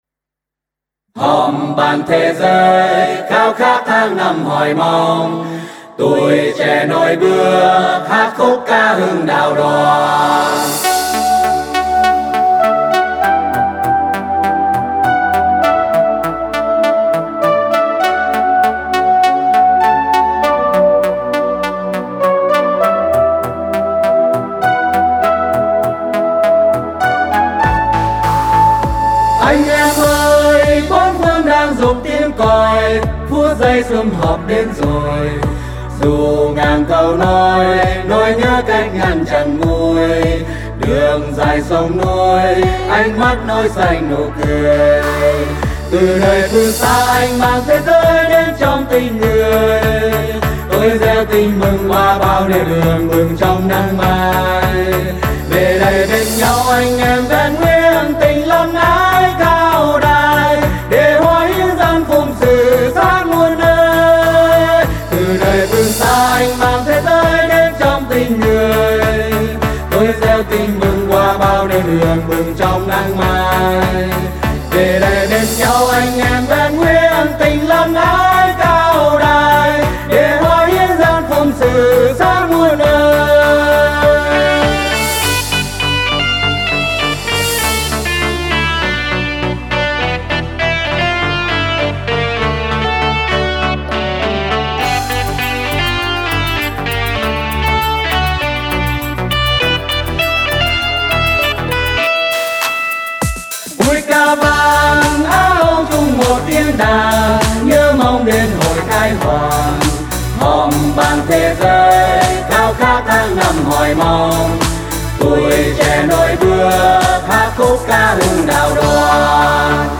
Thể Loại Đạo Ca